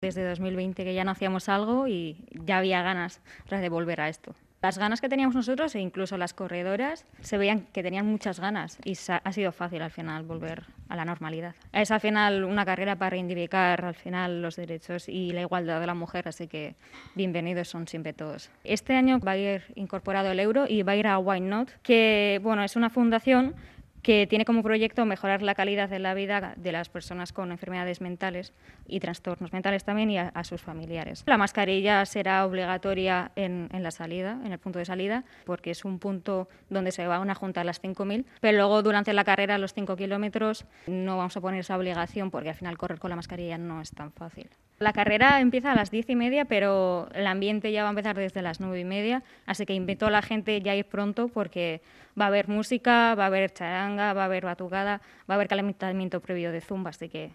tal y como ha destacado entrevistada en Gipuzkoako Kale Nagusia de Onda Vasca